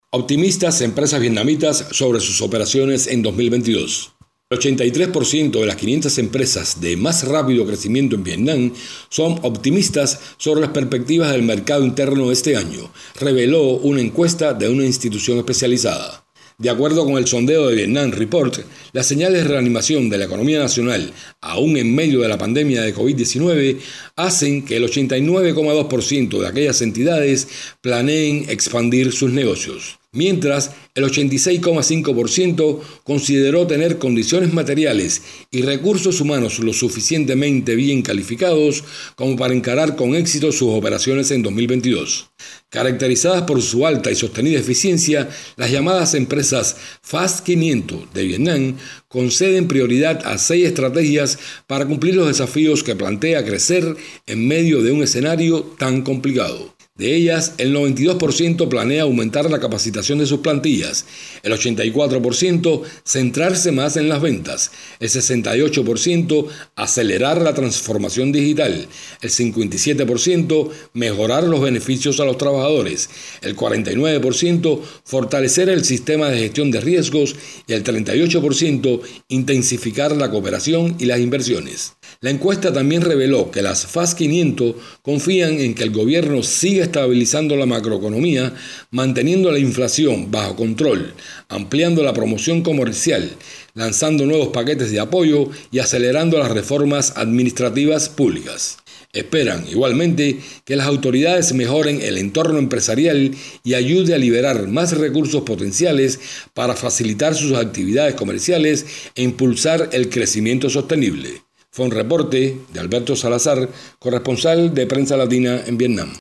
desde Hanoi